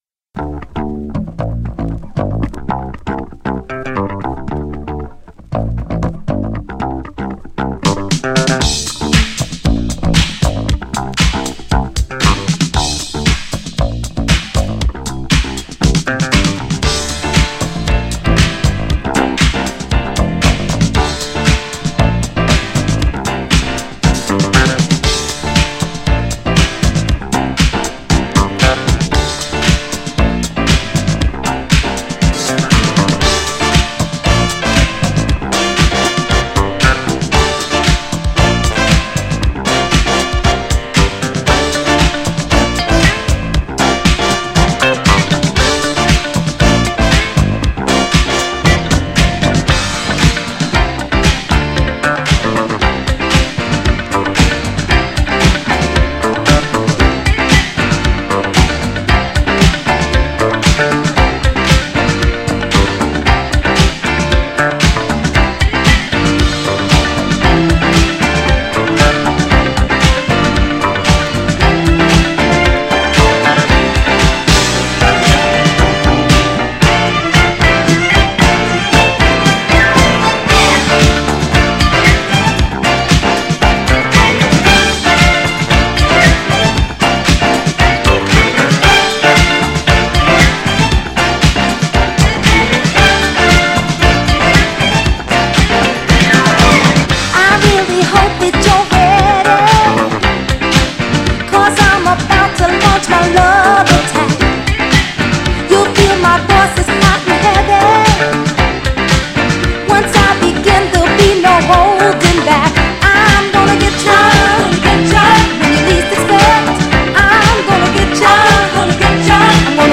中盤のシンセワークもたまりません!!
GENRE Dance Classic
BPM 121〜125BPM